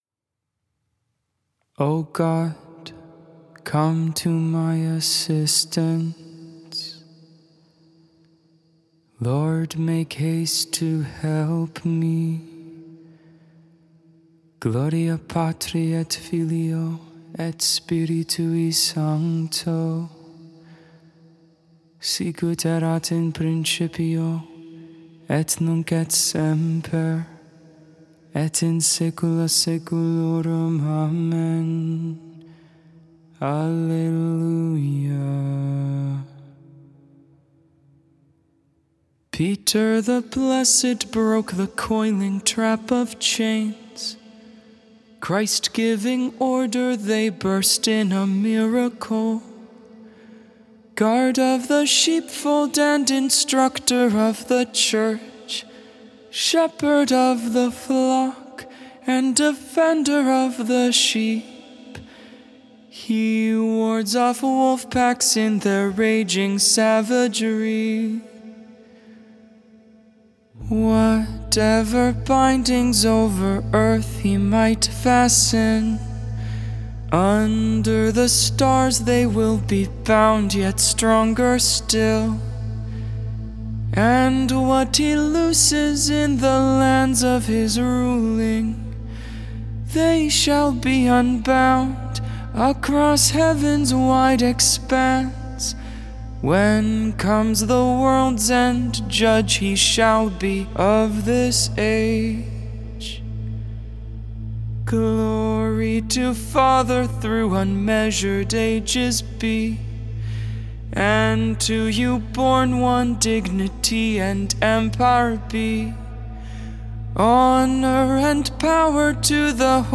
Vespers for the Feast of the Chair of St. Peter! 7th Tuesday in Ordinary Time, February 22nd, 2022.
original tune, inspired by the Maronite rite
tonus Peregrinus